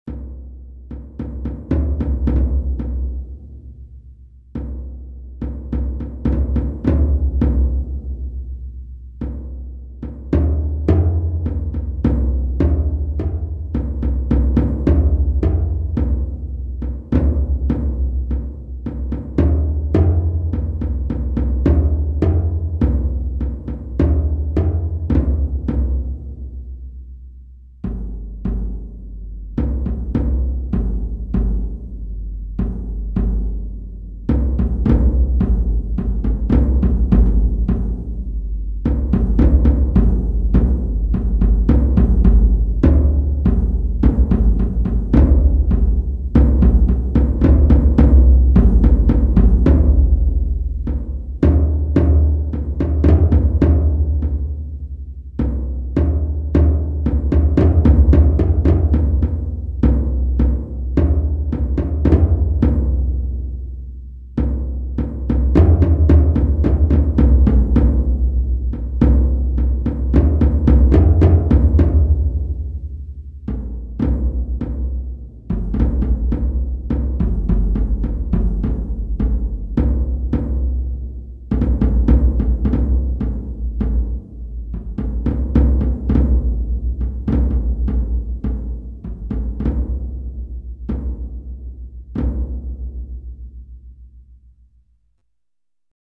В наше время создается огромное количество псевдо-египетской музыки.